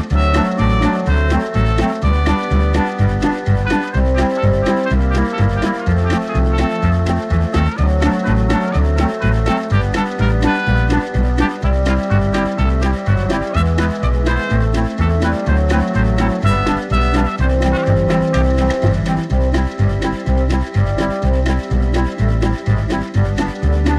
No Ukulele In Key Of C Oldies (Male) 3:05 Buy £1.50